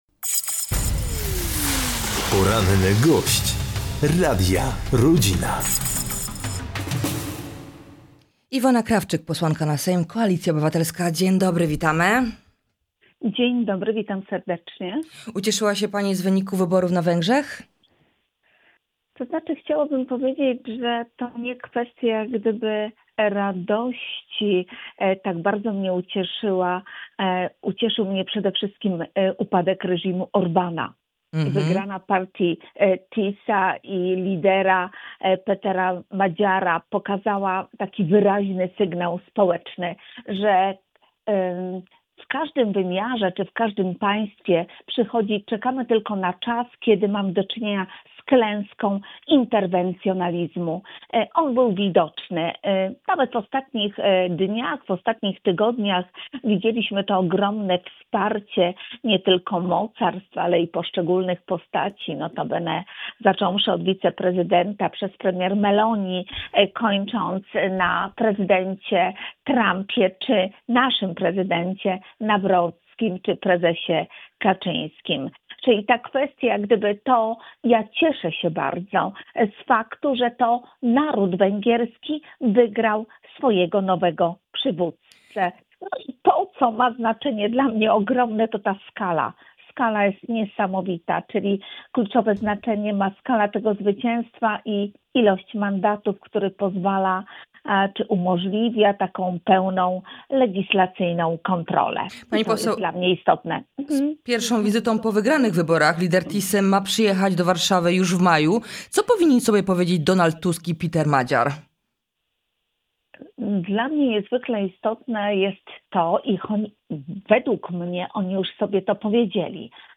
O wyborach na Węgrzech, sytuacji w TK, posiedzeniu Rady Krajowej Koalicji Obywatelskiej, która wybrała władze na nową kadencję, 10 na wybory, edukacji zdrowotnej i aferze pedofilskiej w Kłodzku rozmawiamy z Iwoną Krawczyk, posłanką na Sejm z KO.